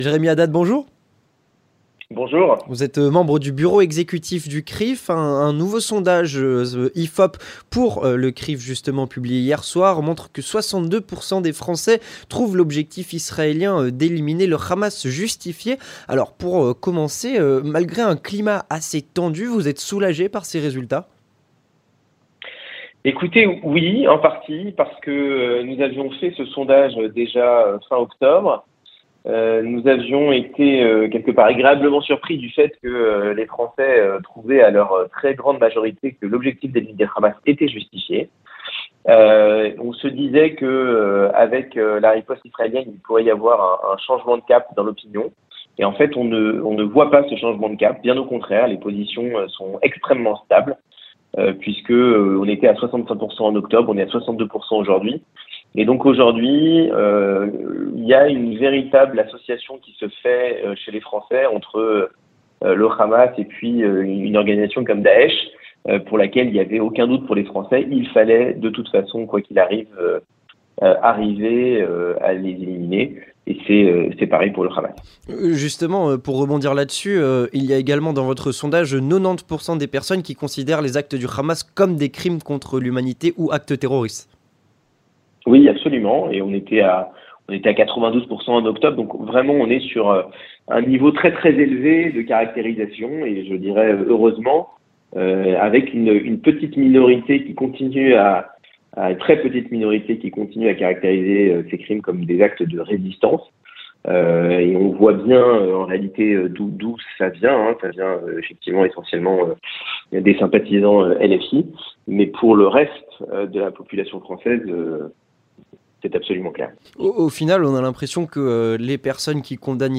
3. L'entretien du 18h